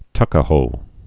(tŭkə-hō)